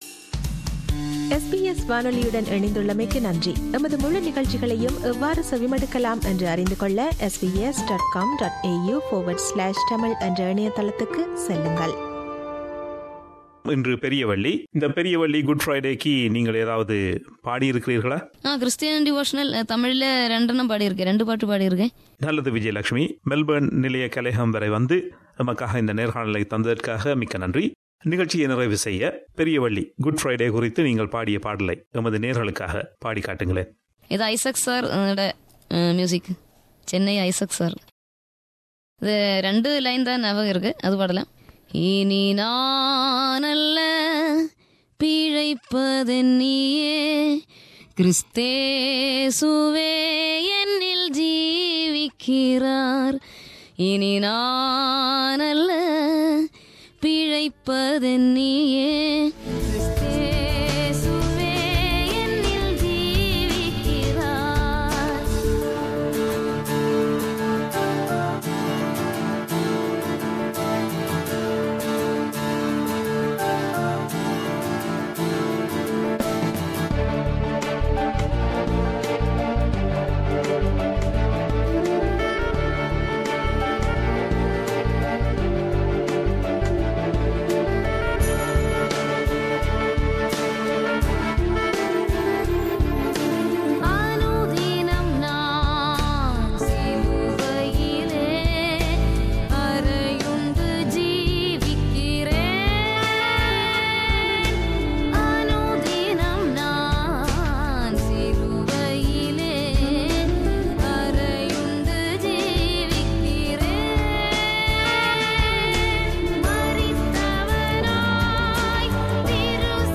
Vaikom Vijayalakshmi talks about a Christian Devotional song she has sung and renders a few lines as well.